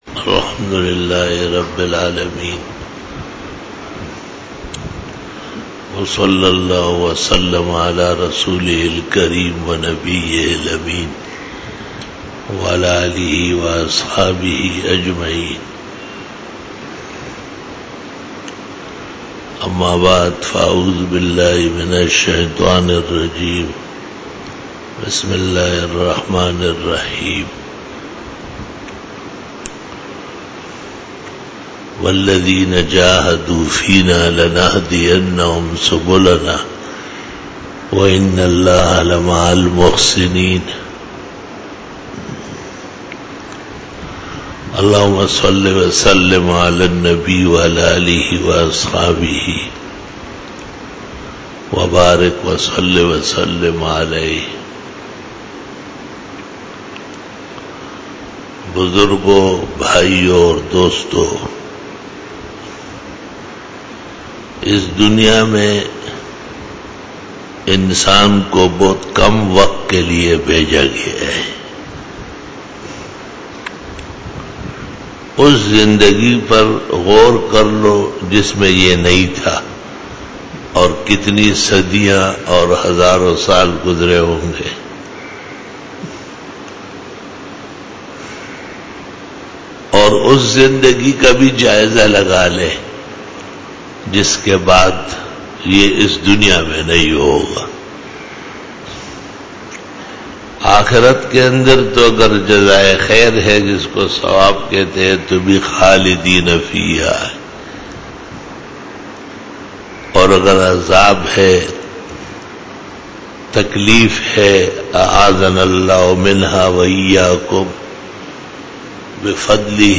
41 BAYAN E JUMA TUL MUBARAK (12 October 2018) (02 Safar 1440H)
Khitab-e-Jummah 2018